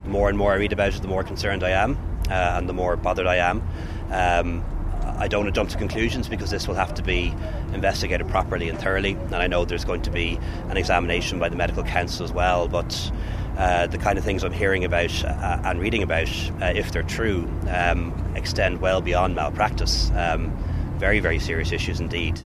Taoiseach Leo Varadkar said he can’t overstate how concerned he is: